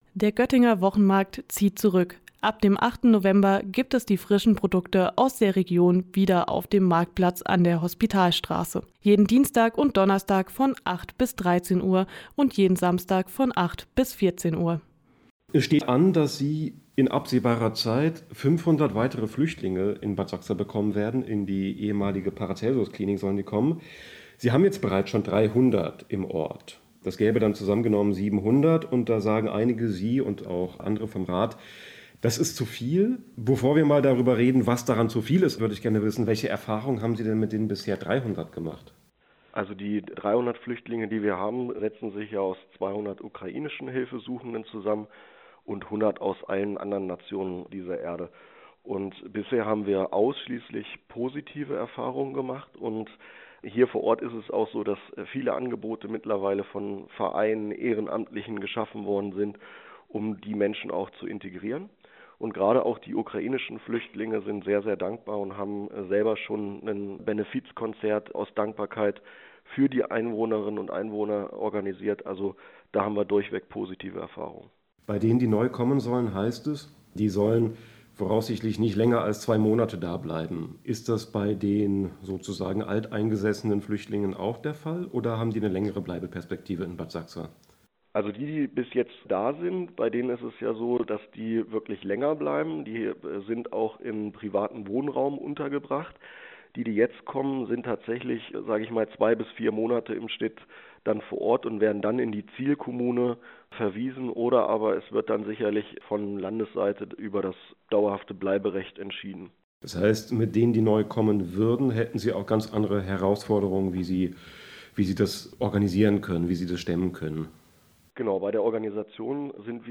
Geplante Flüchtlingsunterkunft in Bad Sachsa - Bürgermeister Daniel Quade im Interview